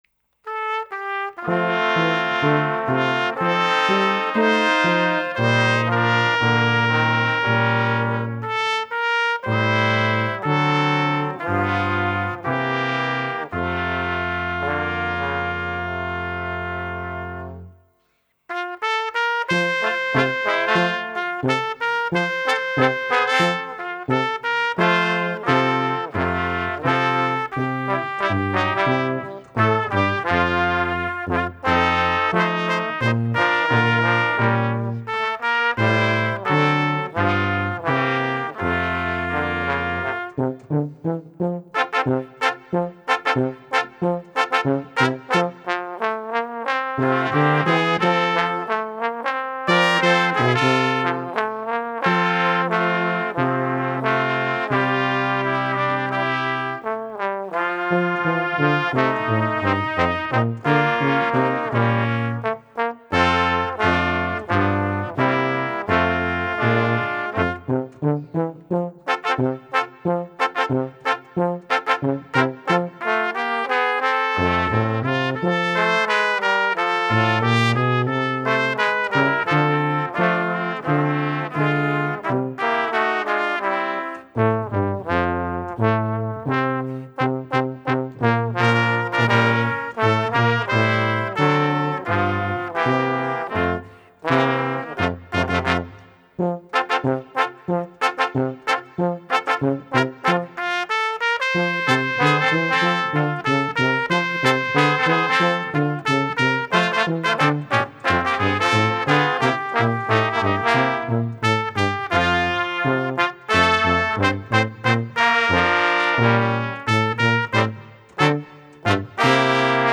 Gattung: 4 Blechbläser
Besetzung: Ensemblemusik für 4 Blechbläser